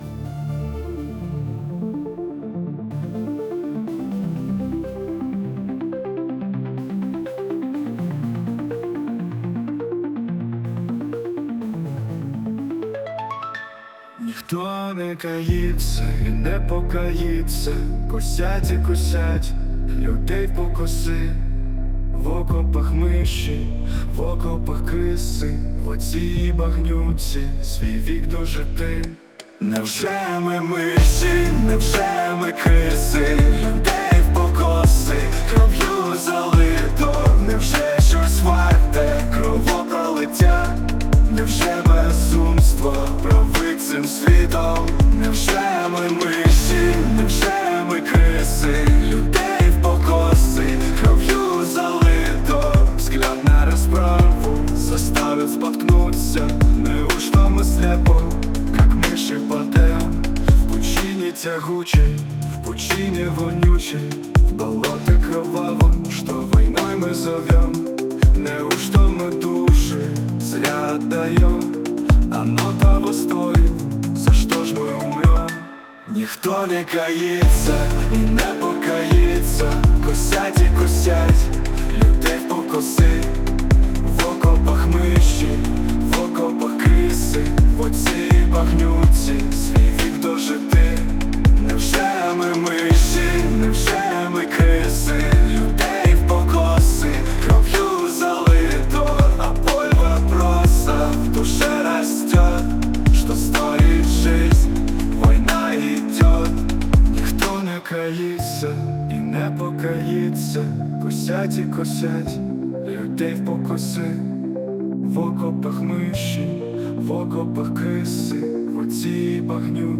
Пісня про те, що все то лиш мясорубка.